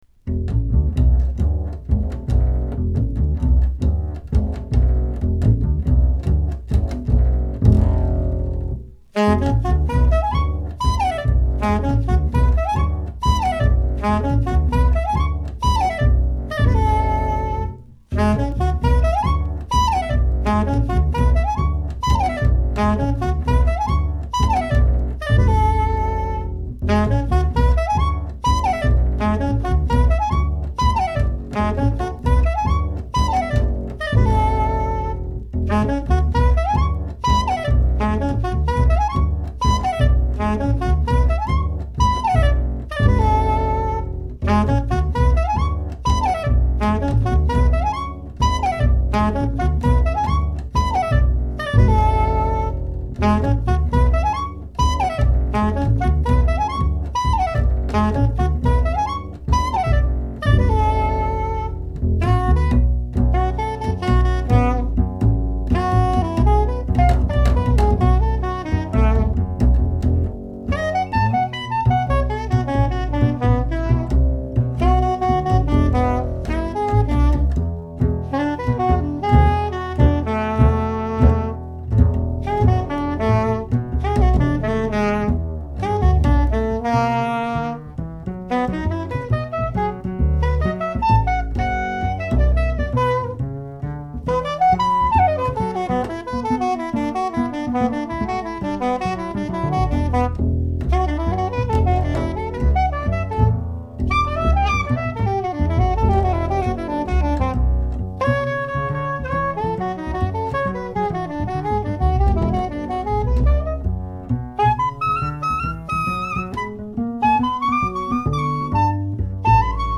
discription:Stereo